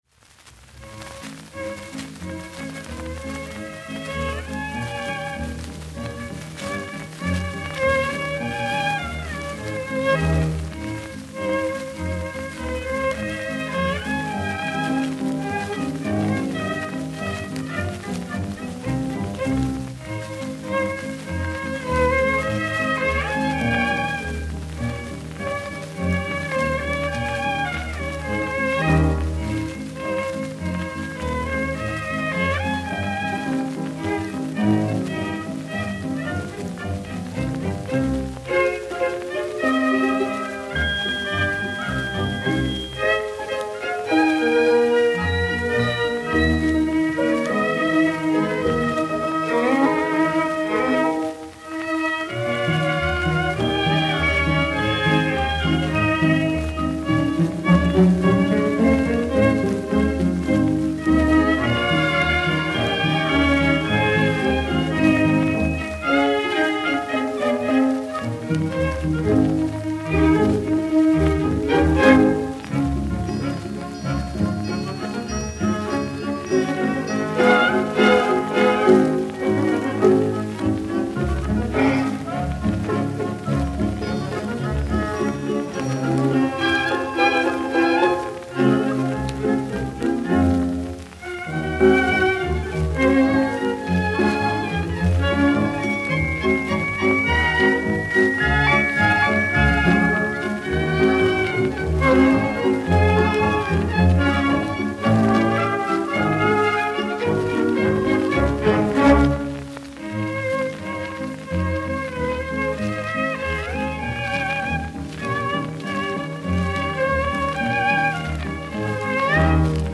Instrumental music